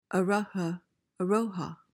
PRONUNCIATION:
(UH-ruh-ha, uh-RO-ha)